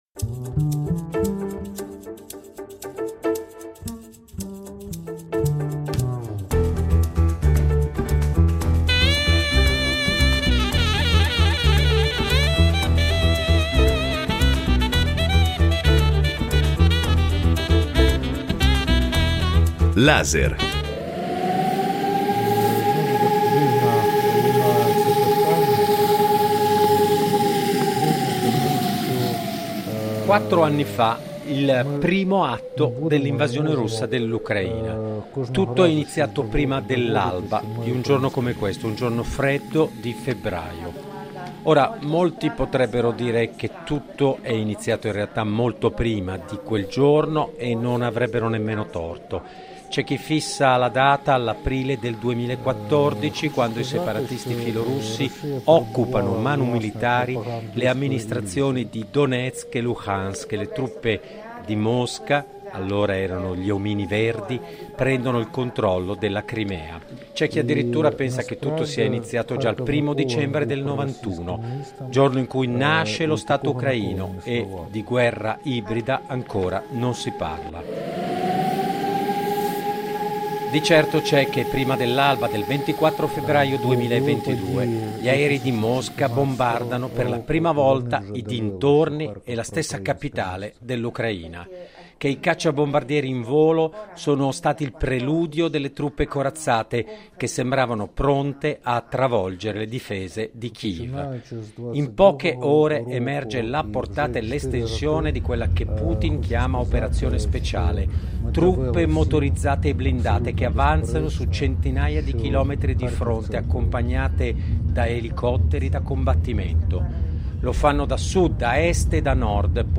Reportage dalla roccaforte ucraina